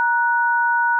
dtmf_hash.ogg